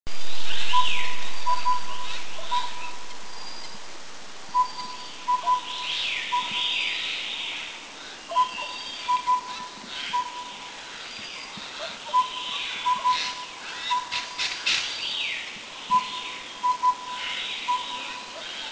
2010 Taiwan tour
Collared Owlet
Glaucidium brodiei
CollaredOwlet.mp3